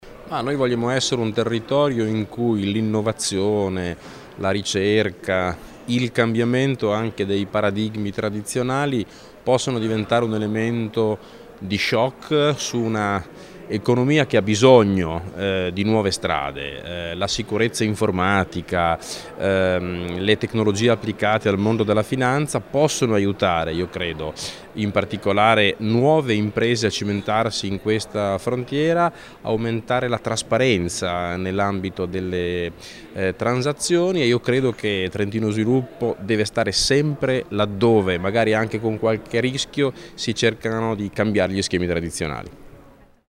Scarica il file 13 dicembre 2017 INT AUDIO ass Olivi seminario Bitcoin.MP3